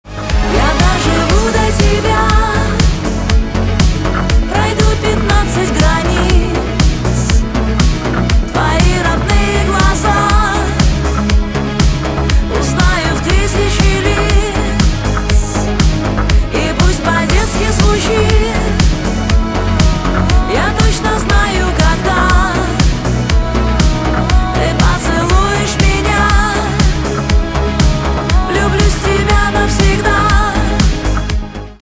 русский рок , гитара , барабаны
романтические